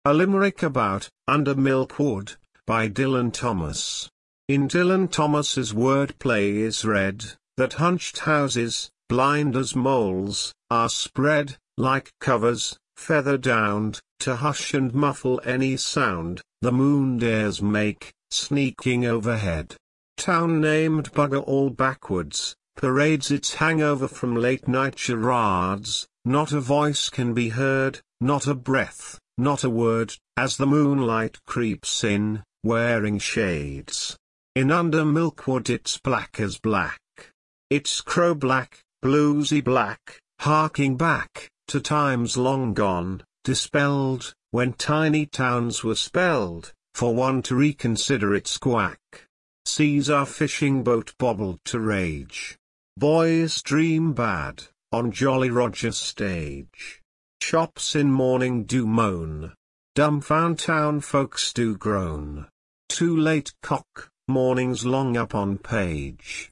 The English you speak sure has some different pronunciation from mine for words like Charade!